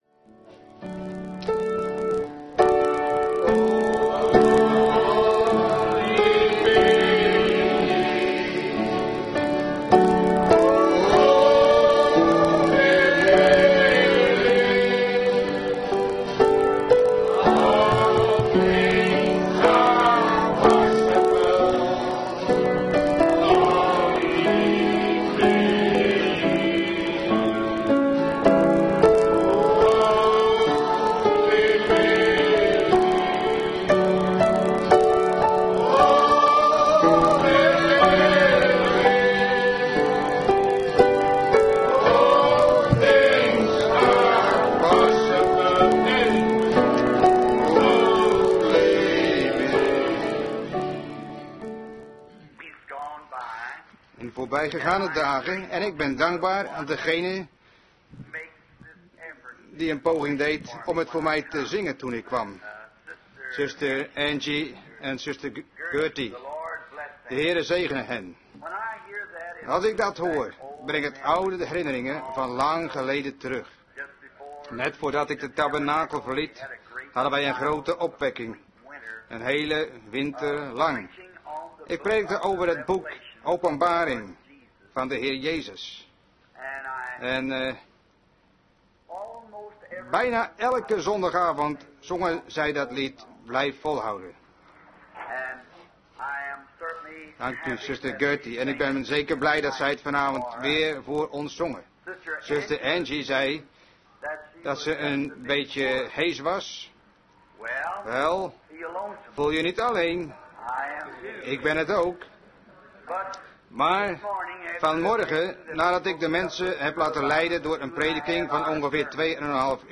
Vertaalde prediking "God keeps His Word" door W.M. Branham te Branham Tabernacle, Jeffersonville, Indiana, USA, 's avonds op zondag 20 januari 1957